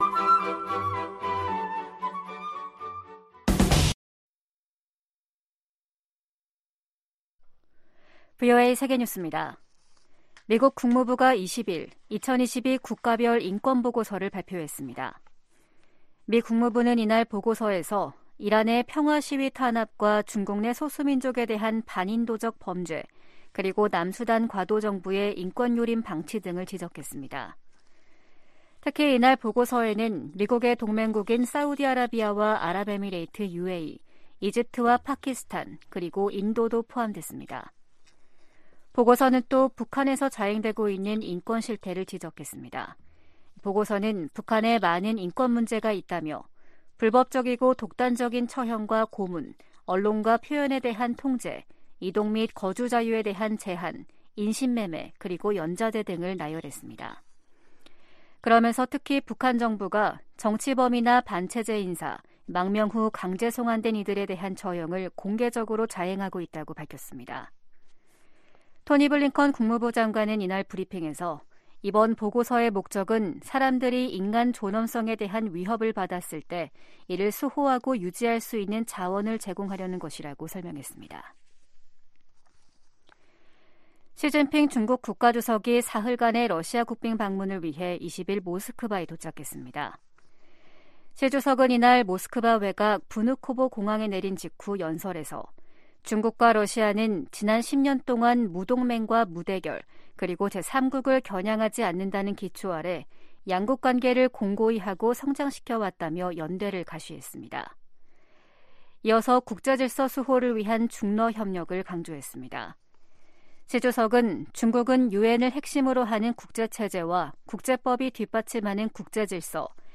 VOA 한국어 아침 뉴스 프로그램 '워싱턴 뉴스 광장' 2023년 3월 21일 방송입니다. 북한은 김정은 국무위원장이 참관한 가운데 한국의 주요 대상을 겨냥한 핵 반격 전술훈련을 했다며 또 다시 핵 공격 위협을 가했습니다. 북한의 탄도미사일 발사 등 도발적 행동이 역내 불안정을 초래하고 있다고 미국 인도태평양사령관이 말했습니다. 유엔 안보리 회의에서 미국이 북한 인권과 대량상살무기 문제가 직결돼 있다며 이를 함께 제기해야 한다고 강조했습니다.